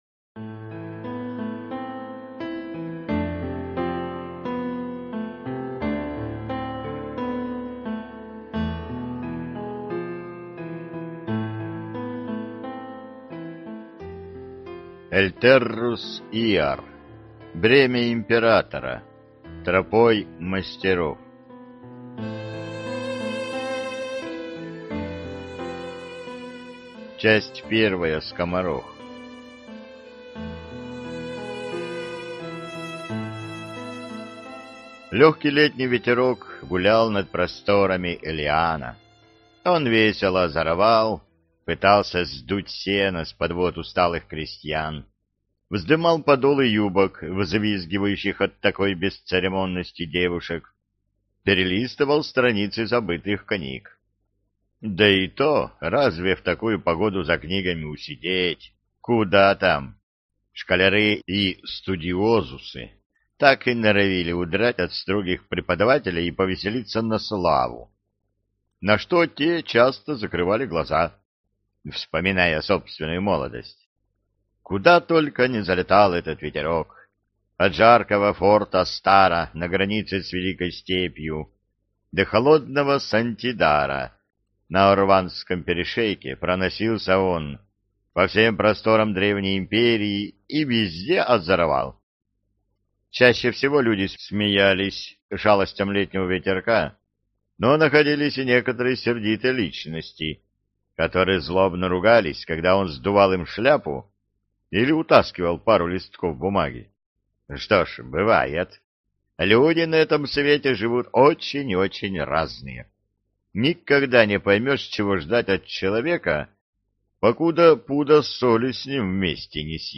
Аудиокнига Бремя императора: Тропой мастеров | Библиотека аудиокниг